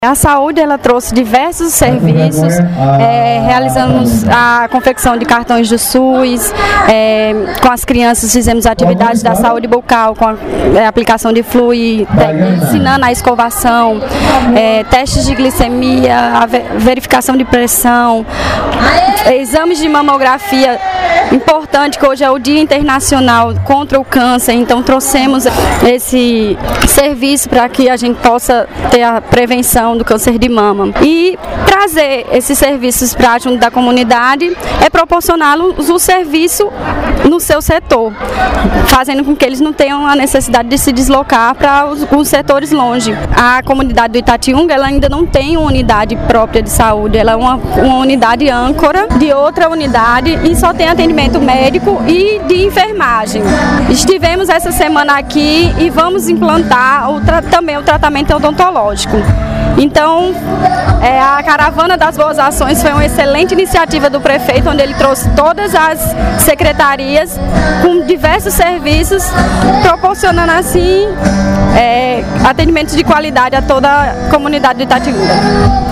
Fala da secretária de Saúde, Andressa Lopes –